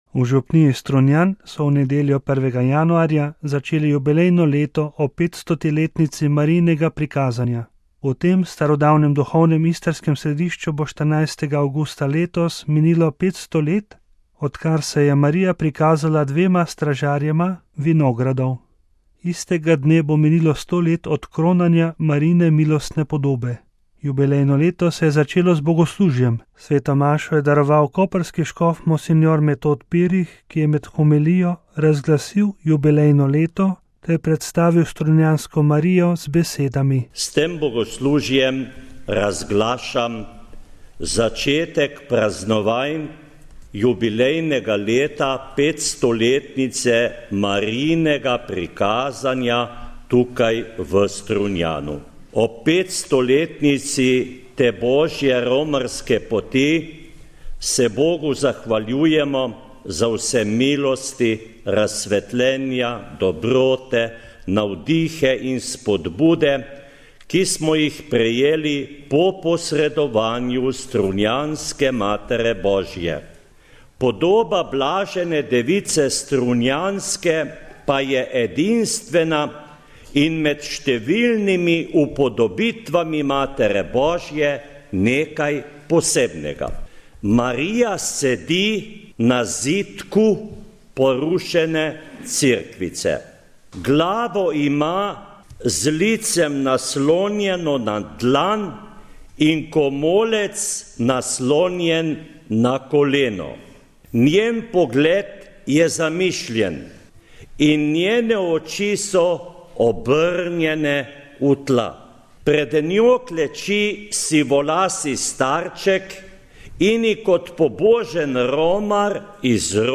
Jubilejno leto se je začelo z bogoslužjem.